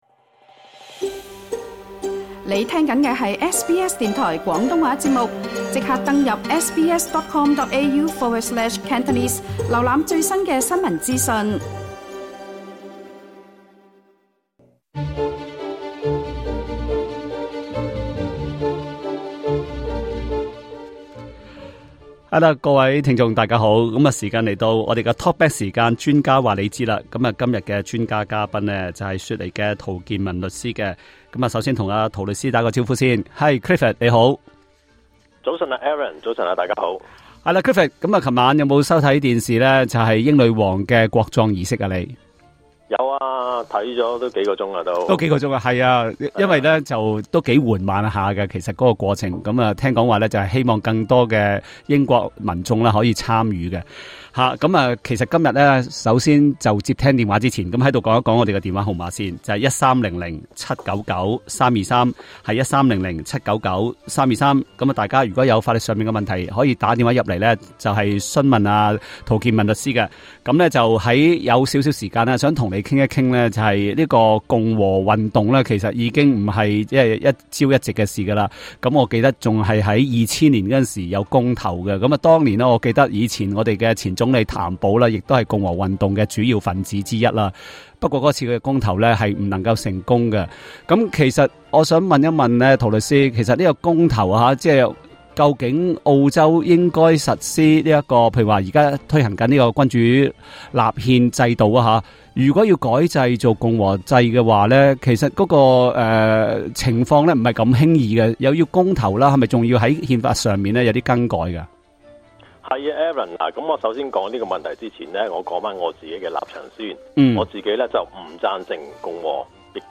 另外，他還解答聽眾的提問，例如在遺囑中要餽贈某人一些財物，是否需要該人的任何身份證明文件？